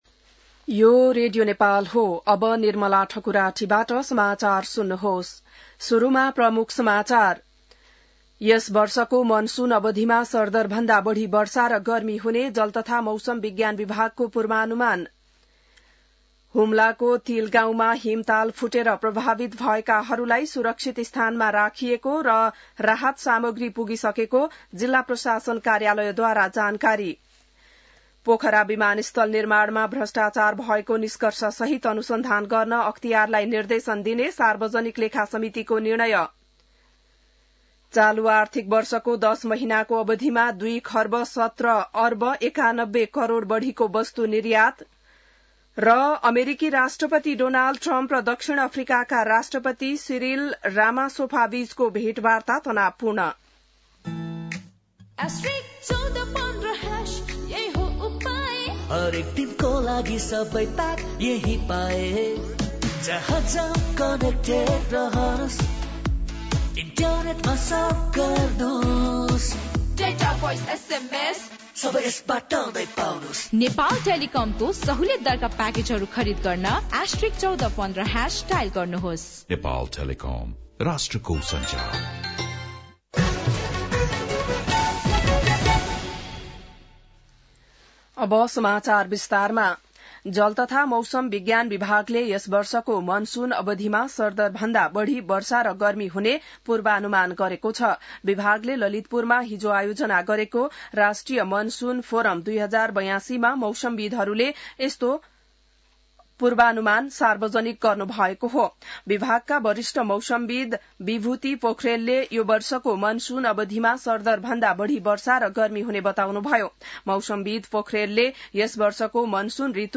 बिहान ७ बजेको नेपाली समाचार : ८ जेठ , २०८२